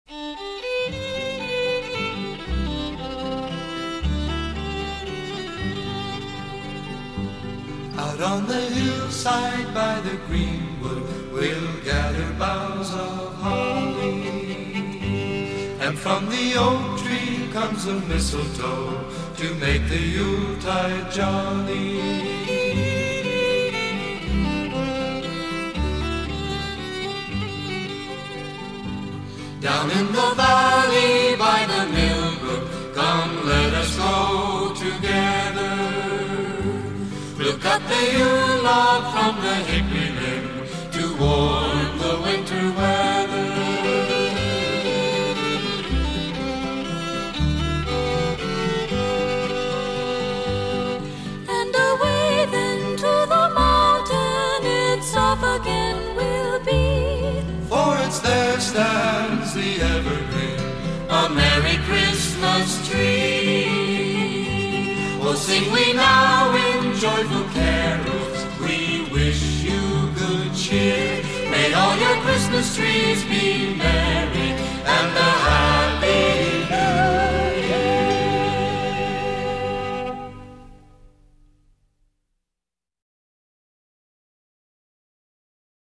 which are things of simple yet heartrending beauty.